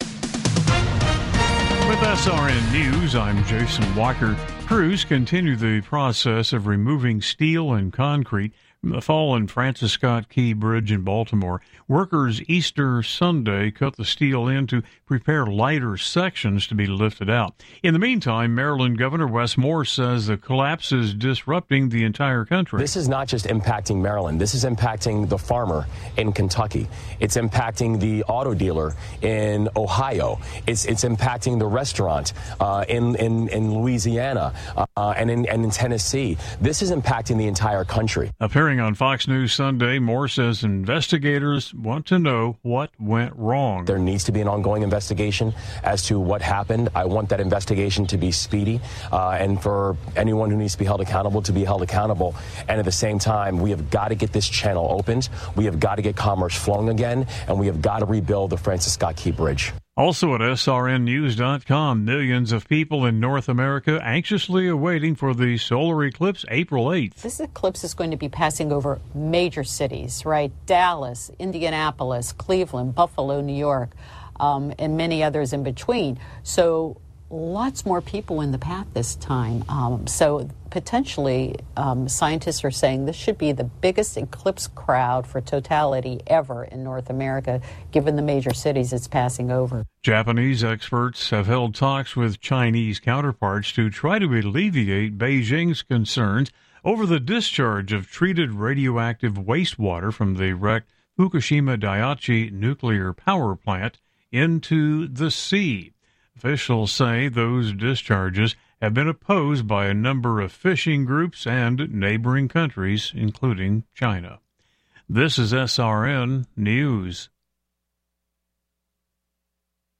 Latest news stories from around the world brought to you at the top of the hour